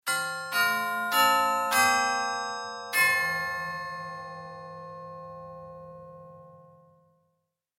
جلوه های صوتی
دانلود صدای ساعت 23 از ساعد نیوز با لینک مستقیم و کیفیت بالا
برچسب: دانلود آهنگ های افکت صوتی اشیاء دانلود آلبوم صدای ساعت از افکت صوتی اشیاء